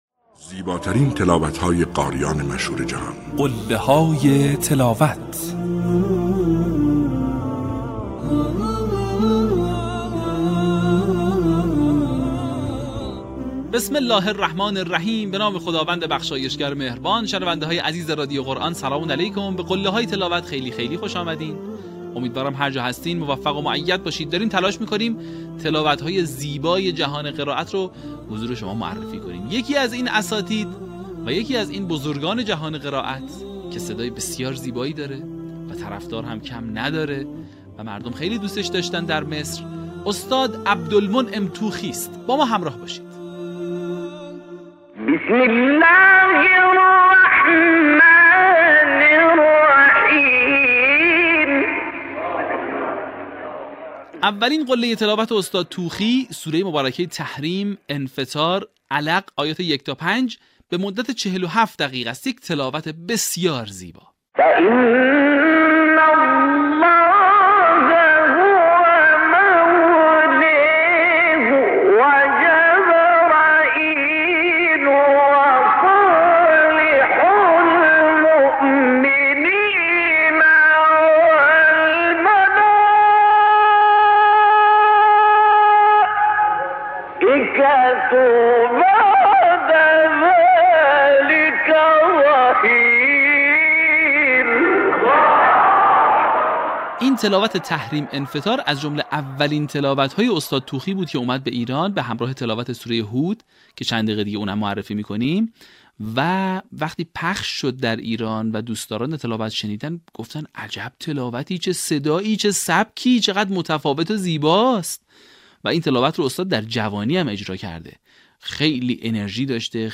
در قسمت پانزدهم، فرازهای شنیدنی از تلاوت‌های به‌یاد ماندنی استاد عبدالمنعم طوخی را می‌شنوید.
برچسب ها: عبدالمنعم طوخی ، قله های تلاوت ، تلاوت تقلیدی ، فراز تقلیدی ، تلاوت به یاد ماندنی